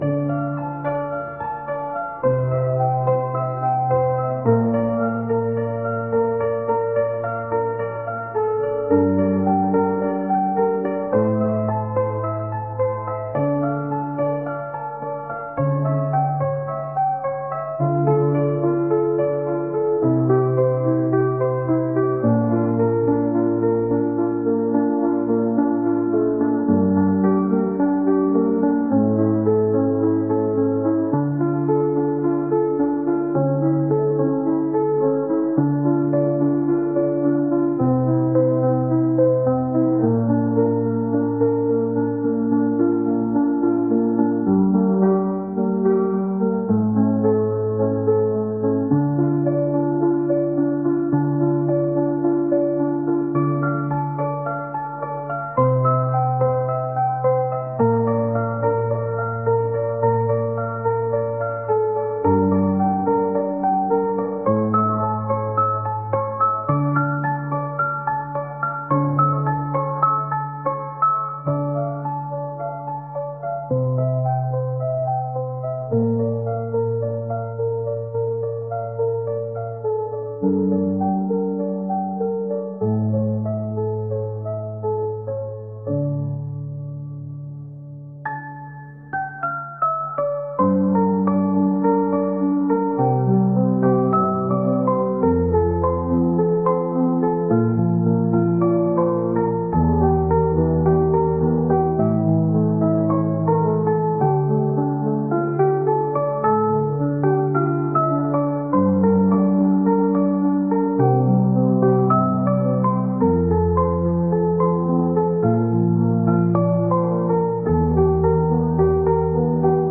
「癒し、リラックス」